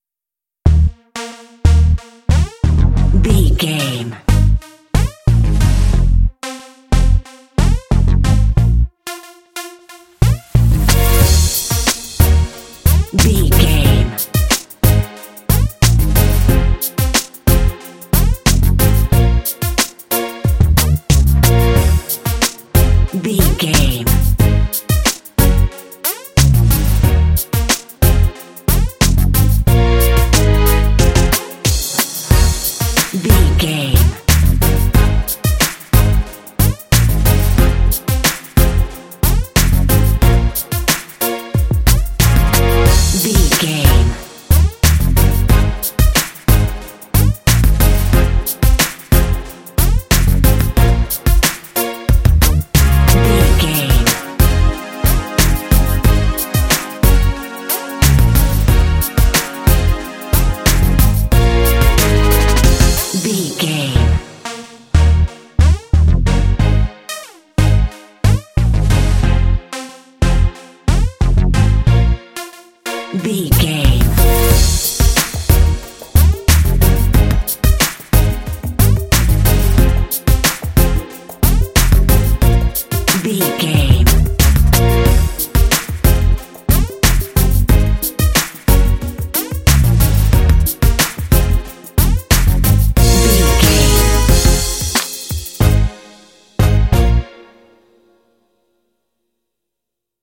Aeolian/Minor
B♭
cool
urban
futuristic
synthesiser
drums
bass guitar
strings
synth-pop